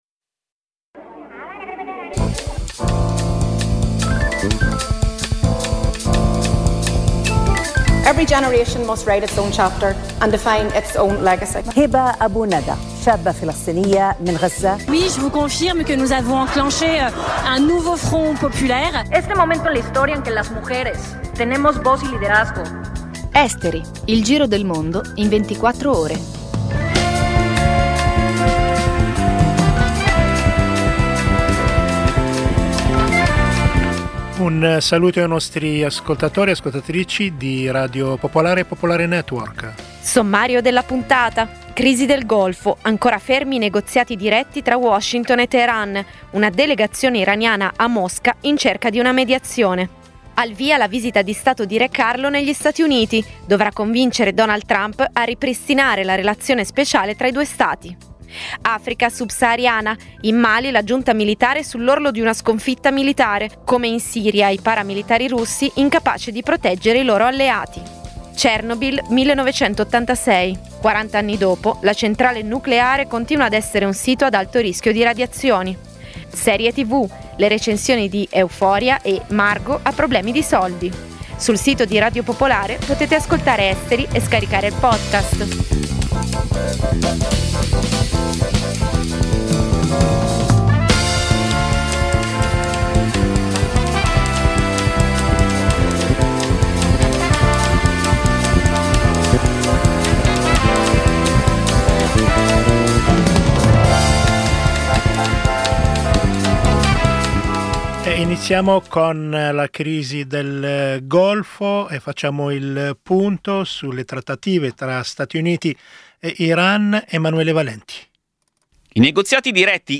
Il programma combina notizie e stacchi musicali, offrendo una panoramica variegata e coinvolgente degli eventi globali.